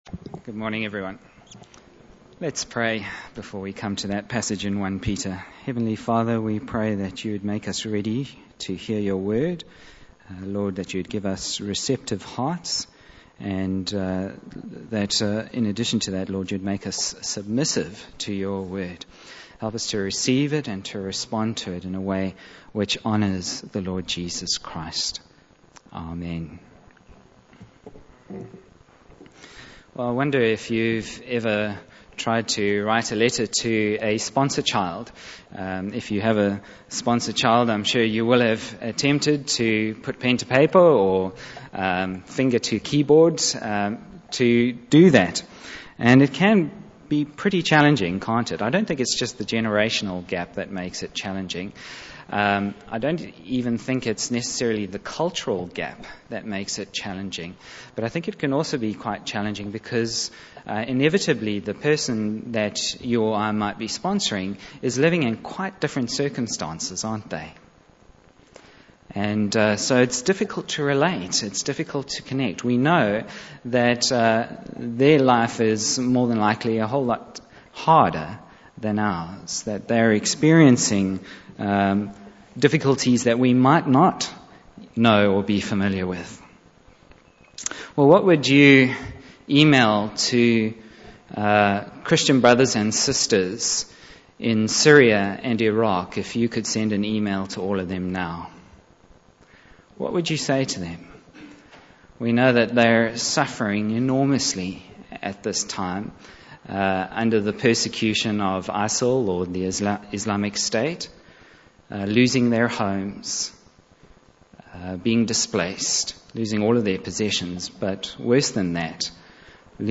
Bible Text: 1 Peter 1:3-9 | Preacher